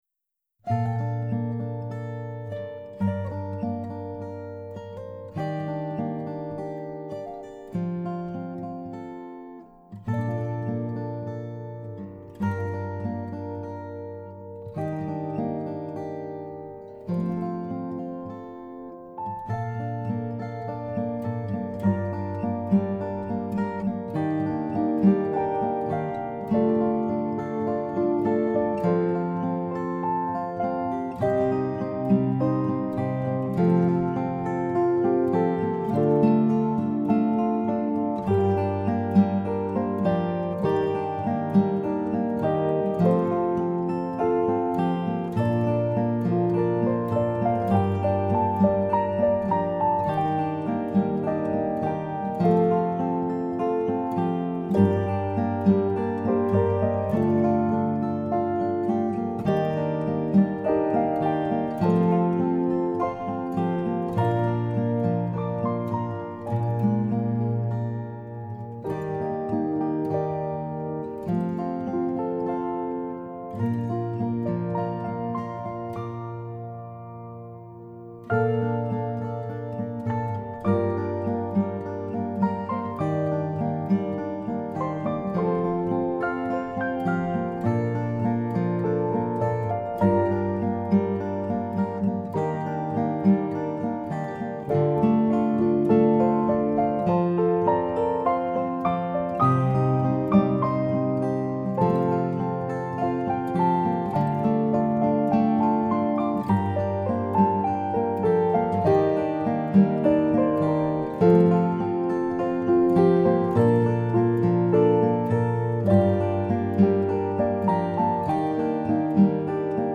Just A Tune Acoustic 2019